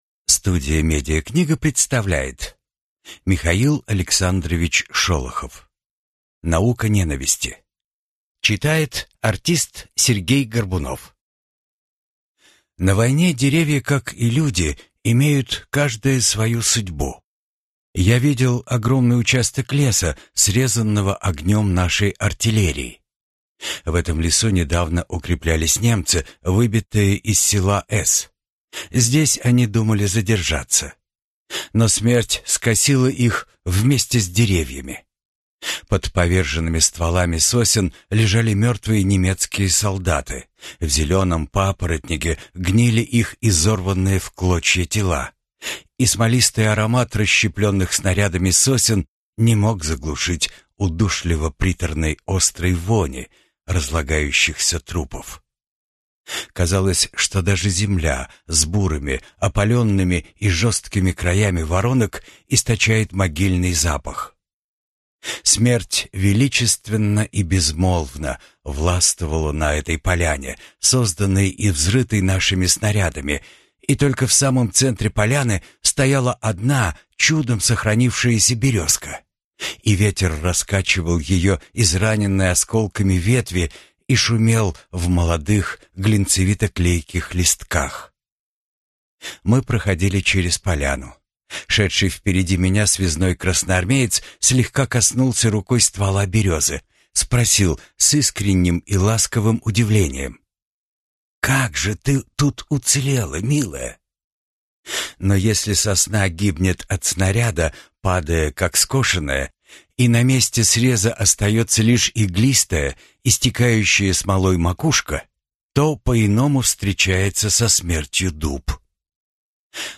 Аудиокнига Наука ненависти | Библиотека аудиокниг